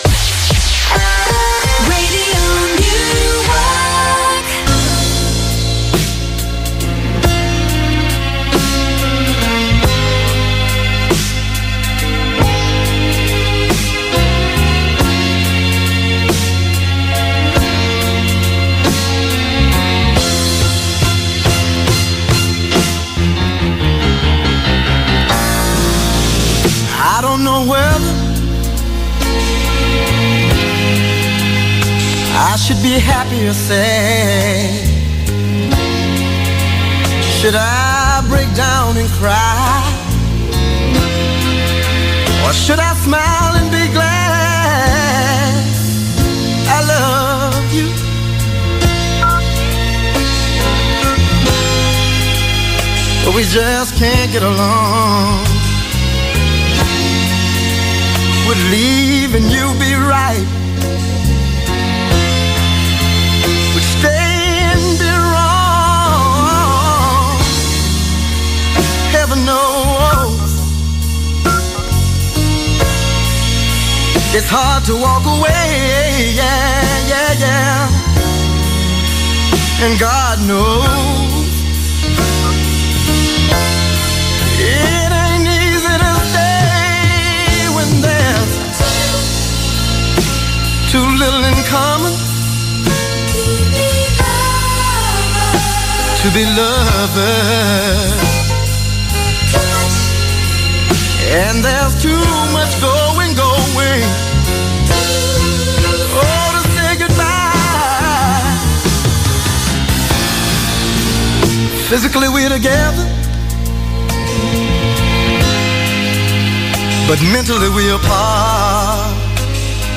Click on the link below to hear the interview broadcast on 16th April 2018